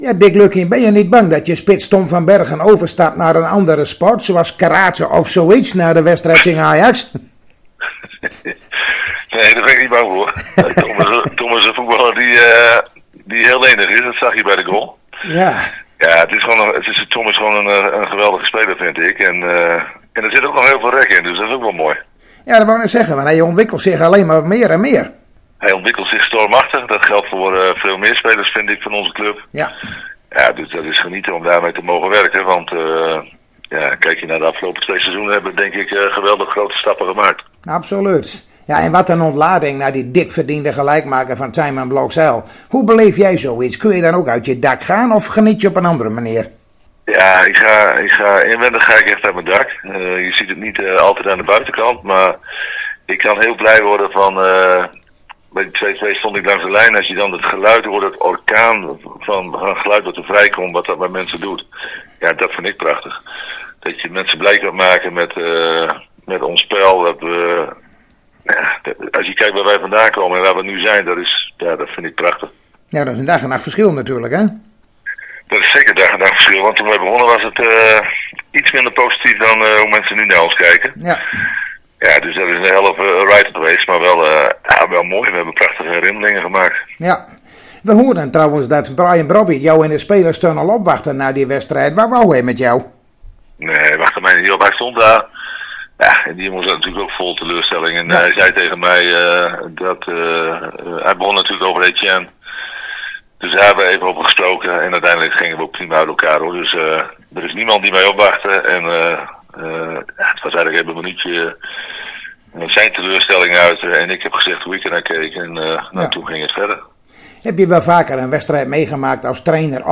Zojuist spraken wij weer met trainer Dick Lukkien van FC Groningen en dat is hieronder te beluisteren.